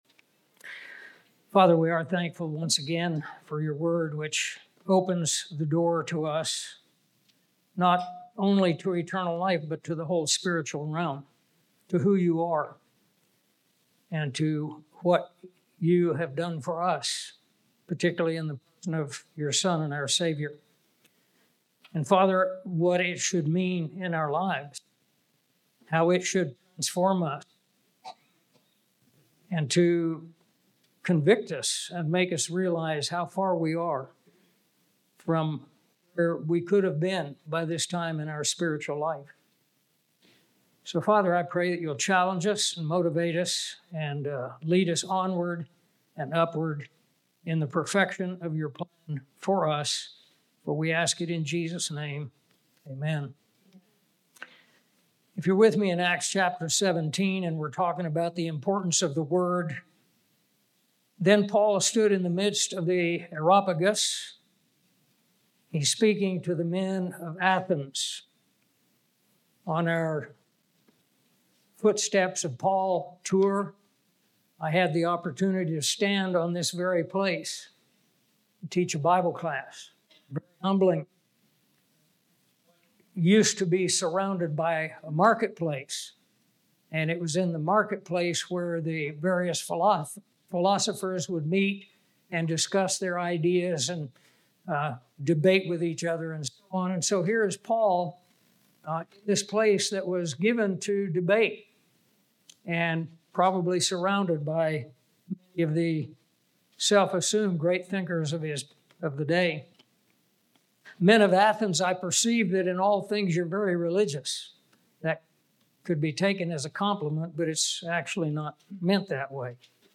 This Bible conference featured seven lesson extracted from The Basics Book.